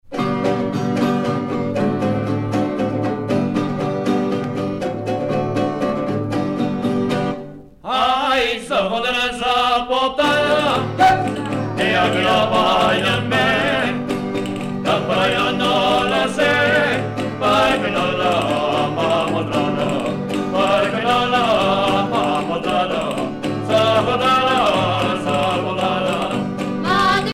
danse : jota (Espagne)
Pièce musicale éditée